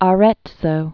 (ä-rĕtsō)